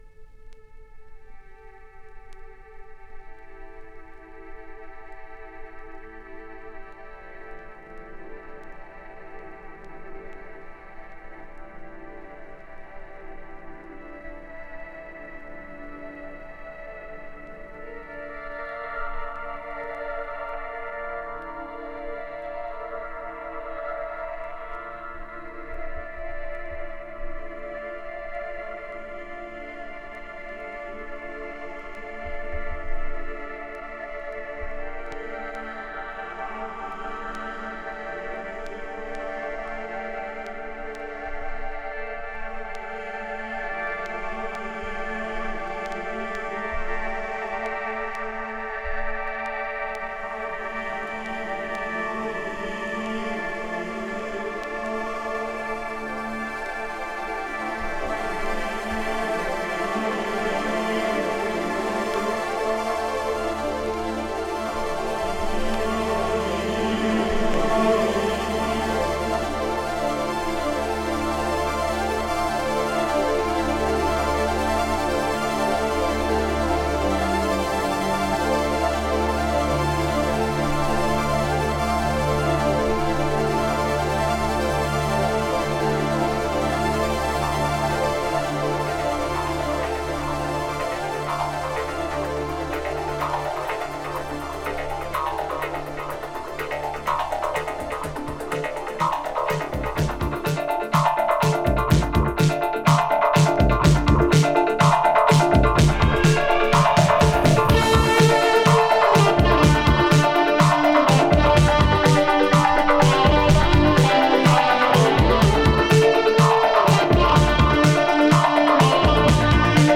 German Electronics Rock！